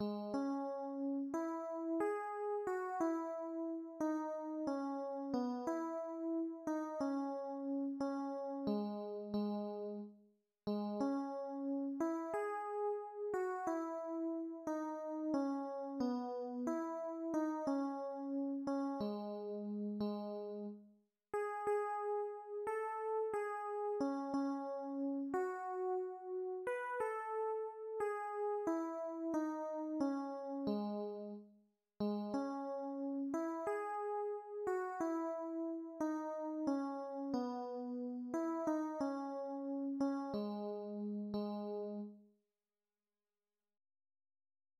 Műfaj magyar népdal
A kotta hangneme H fríg
Előadásmód Moderato
Előadási tempó 96